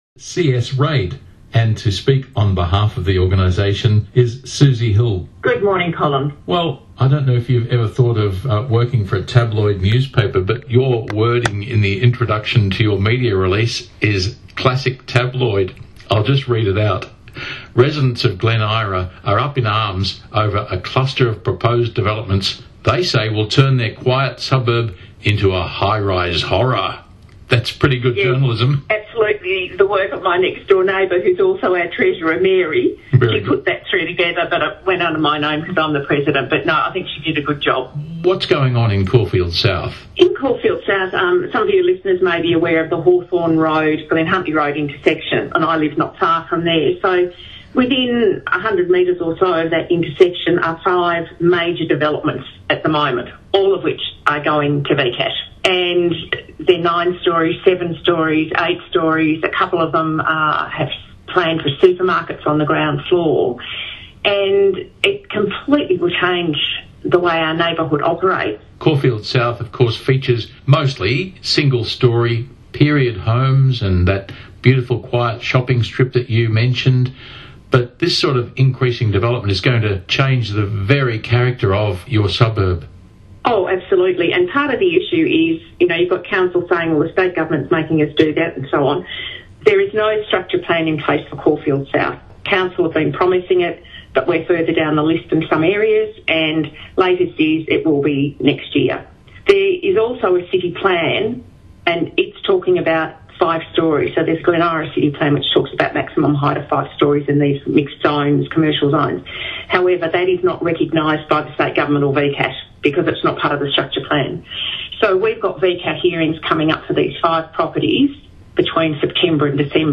Featured below is an interview with a Caulfield South resident on what is happening to this neighbourhood centre.